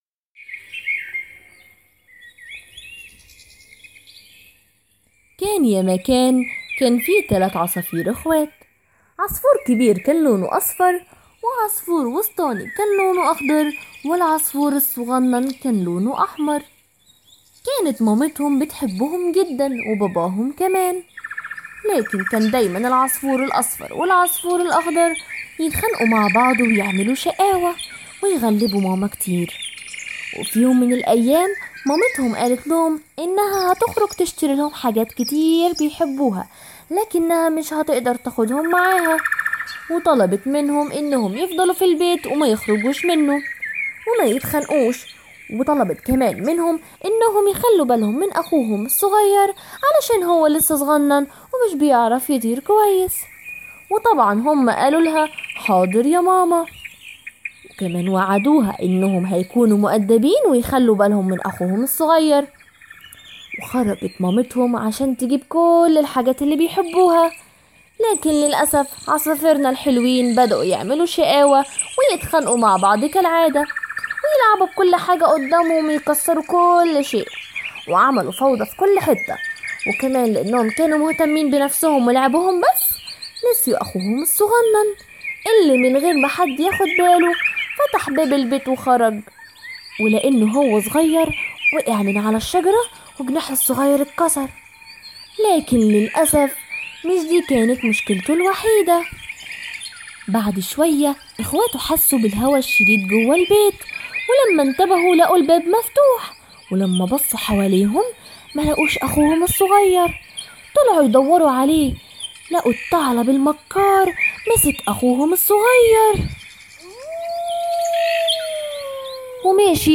قصص مسموعة إقرأ المزيد /wp-content/uploads/2020/05/Story2.mp3 2 مايو 2020 قصة “العصافير والتعلب المكار” لوريم إيبسوم هو ببساطة نص شكلي (بمعنى أن الغاية هي الشكل وليس المحتوى) […]